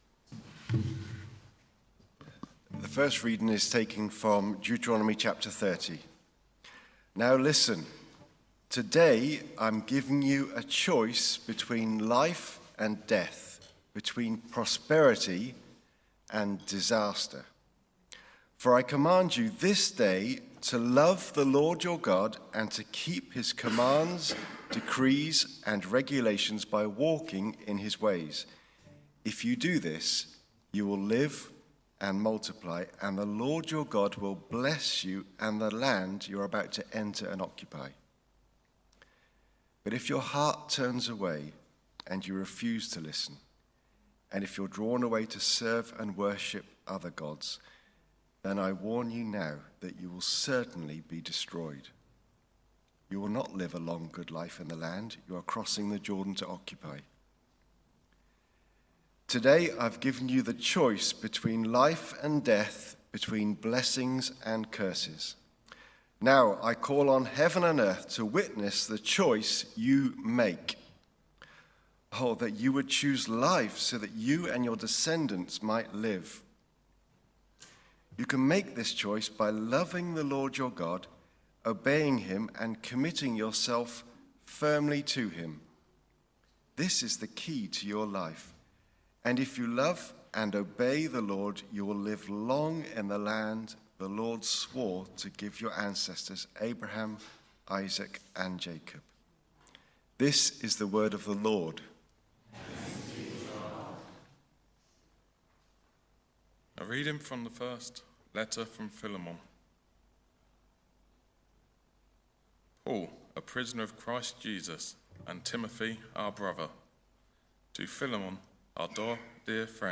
TCE_Sermon-September-7th-2025.mp3